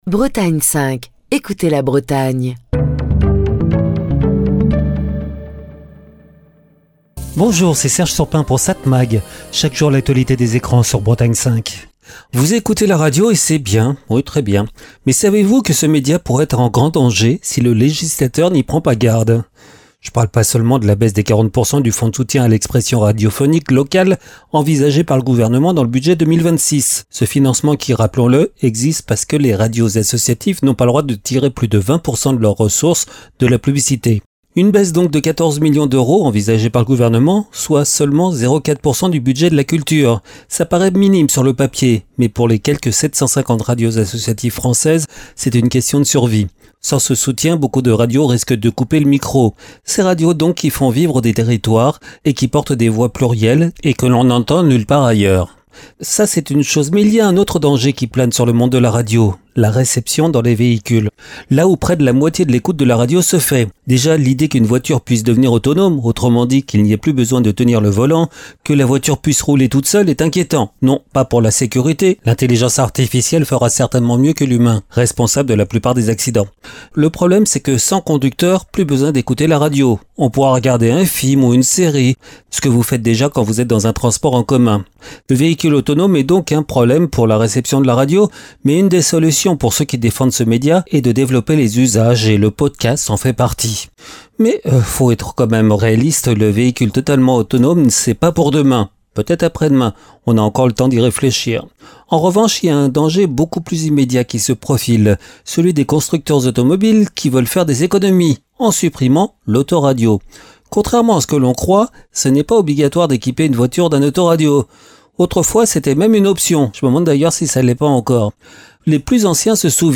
Chronique du 22 octobre 2025.